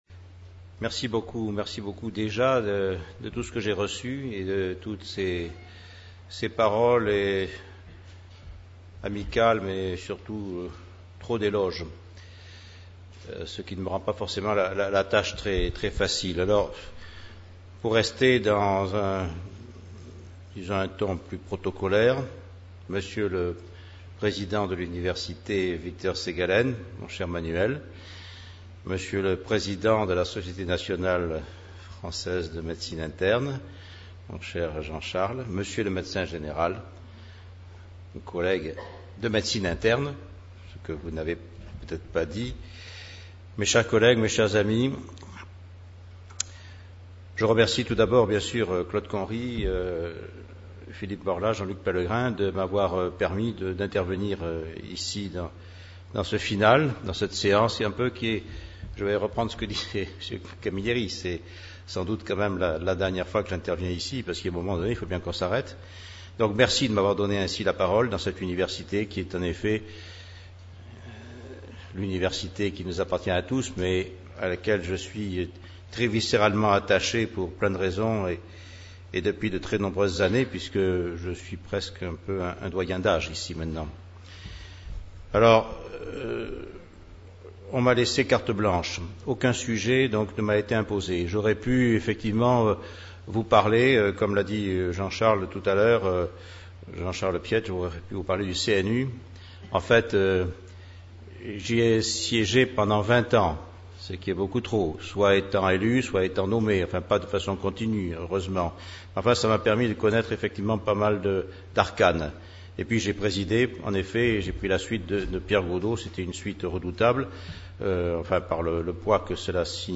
Cette vision dynamique fait état d’inquiétudes quant à l’avenir de ces deux spécialités. La conférence a été donnée à l'Université Victor Segalen Bordeaux 2 à l'occasion du 58ème Congrès de la Société Nationale Française de Médécine Interne (S.N.F.M.I.) le 11 décembre 2008.